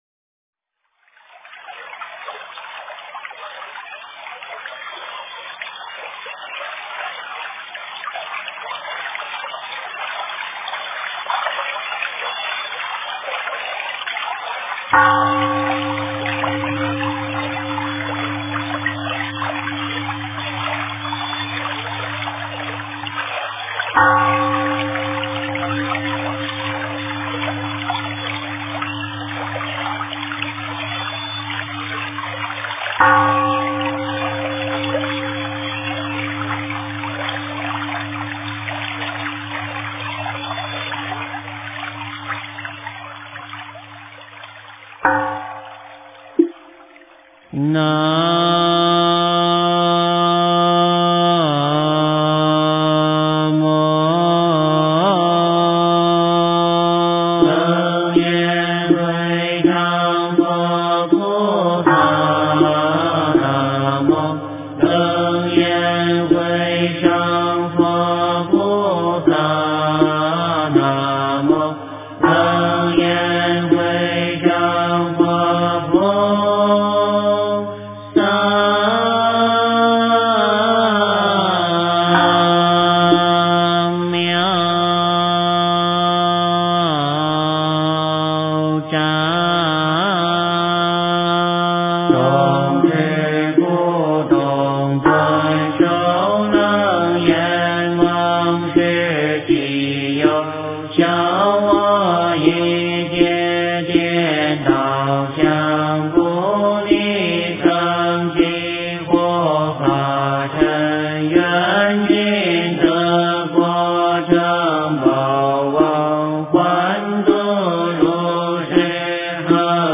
佛音 真言 佛教音乐 返回列表 上一篇： 黄财神心咒--密咒真言 下一篇： 月藏经吉祥文--佚名 相关文章 貧僧有話10說：我弘讲的因缘--释星云 貧僧有話10說：我弘讲的因缘--释星云...